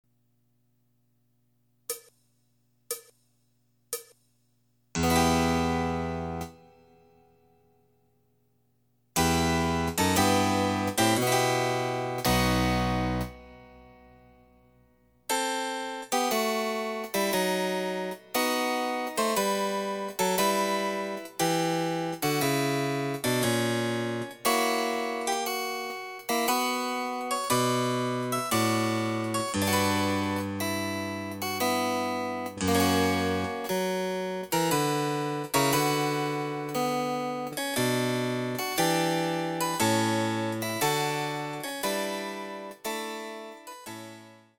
★フルートの名曲をチェンバロ伴奏つきで演奏できる、「チェンバロ伴奏ＣＤつき楽譜」です。
試聴ファイル（伴奏）
（デジタルサンプリング音源使用）
※フルート奏者による演奏例は収録されていません。